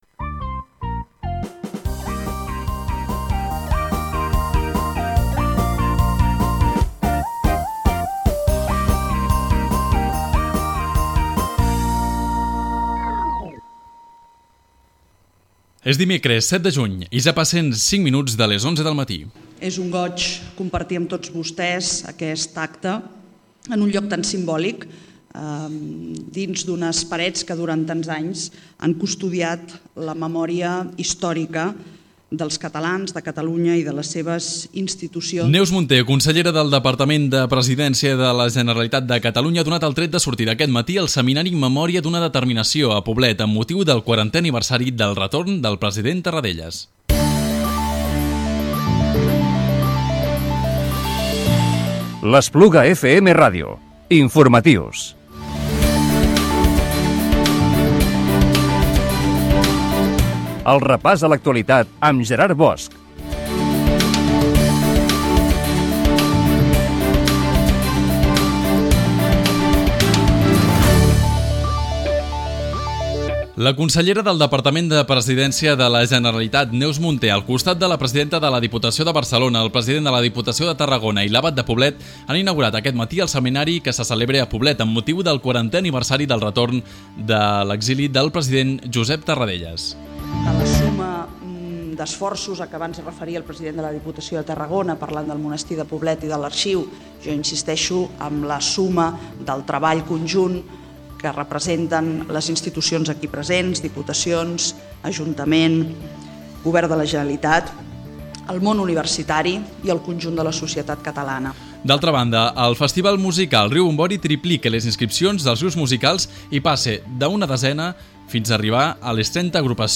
Informatiu diari del dimecres 7 de juny del 2017